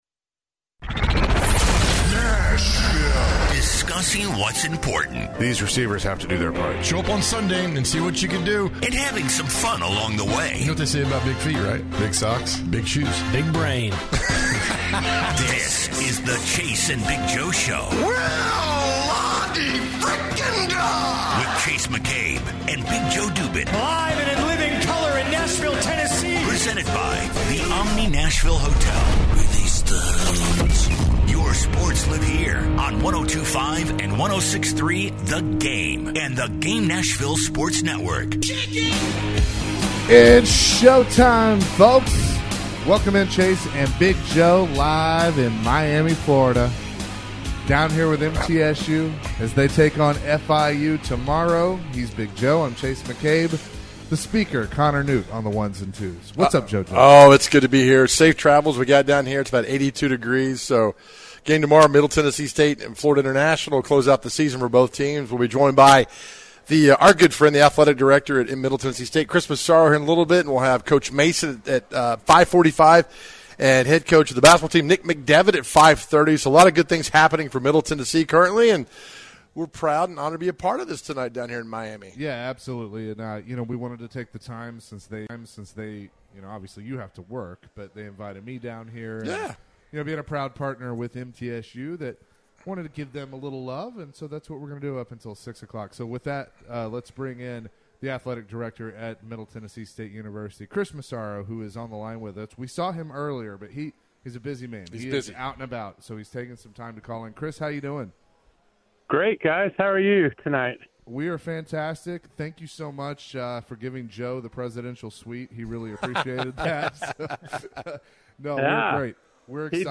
Live from Miami